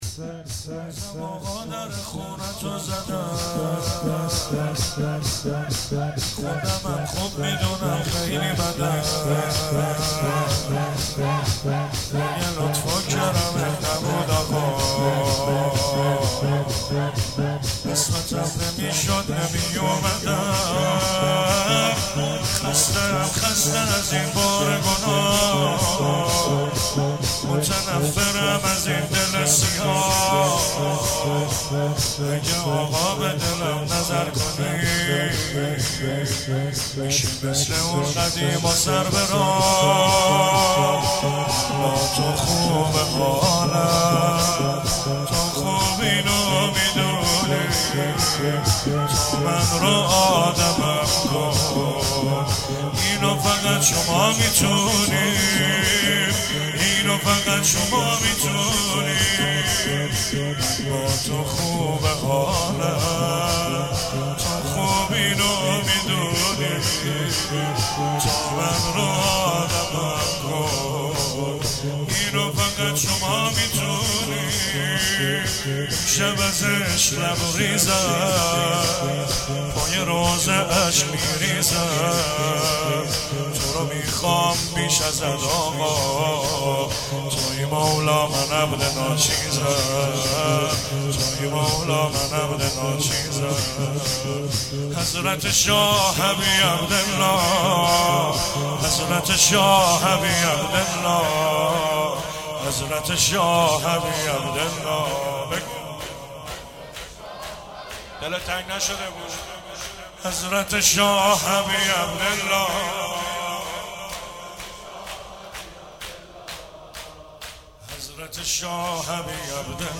مراسم میلاد رسول الله(ص) و امام صادق(ع) 94 :: هیئت علمدار